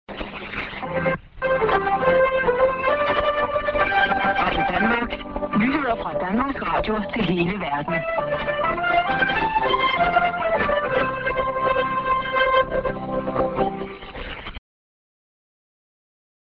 St. R.Denmark ST+ID(man)